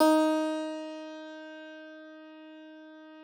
53l-pno11-D2.wav